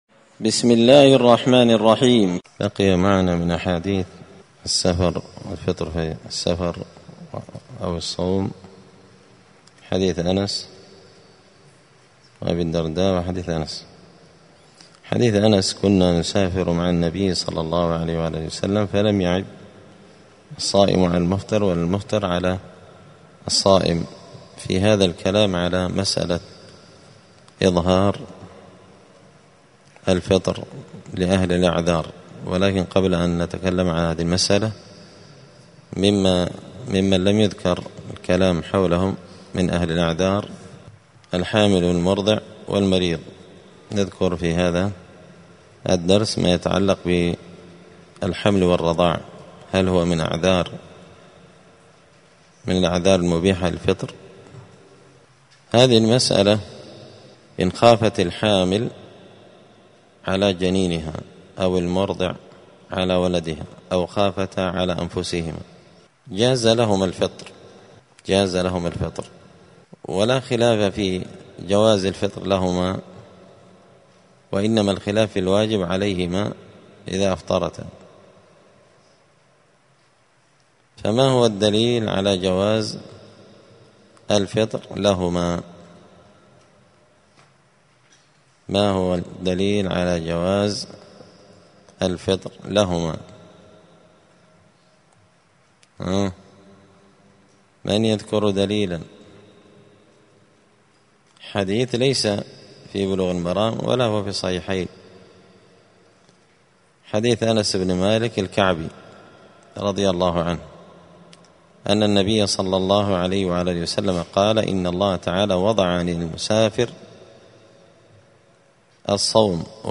دار الحديث السلفية بمسجد الفرقان بقشن المهرة اليمن
*الدرس العشرون (20) {حكم الفطر في رمضان للحامل والمرضع والمريض…}*